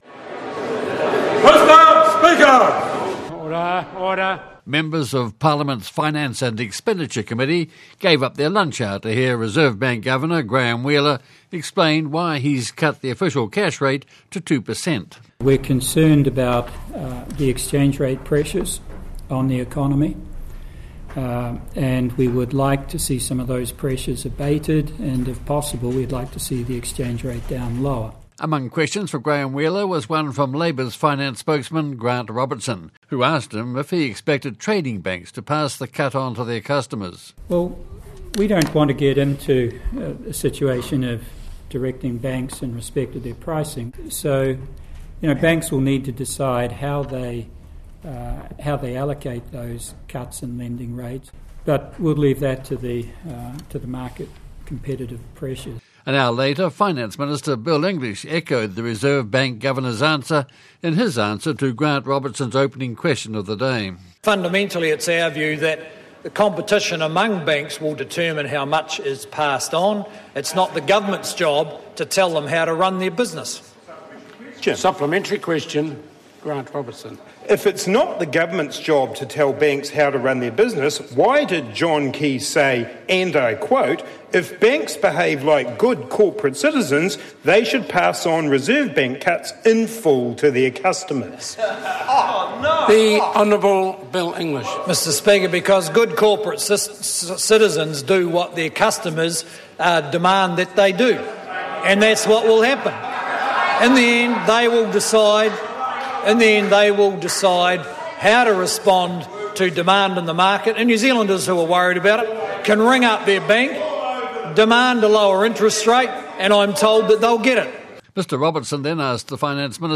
Questions for Reserve Bank governor, Graeme Wheeler, in the committee rooms, and for Finance Minister, Bill English, in the debating chamber, about the Reserve Bank’s cut in the official cash rate to two percent.